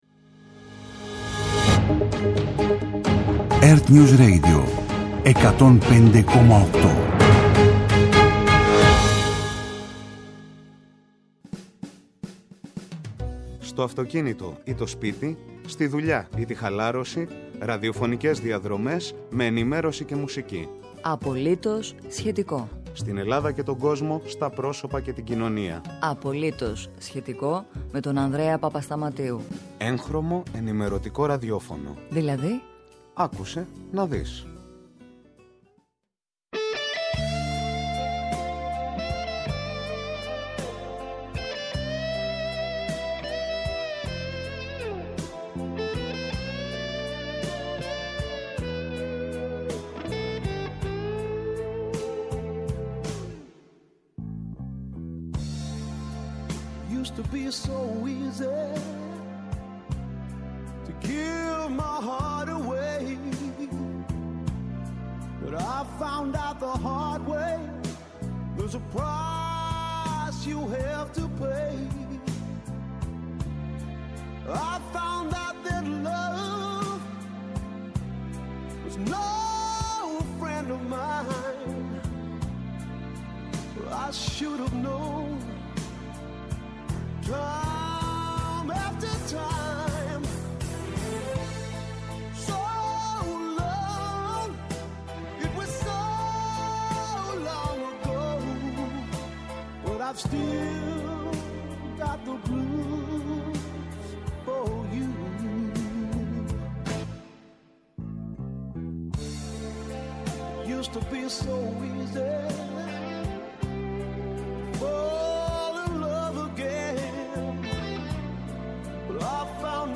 στην αθλητική ενημέρωση
ΕΡΤNEWS RADIO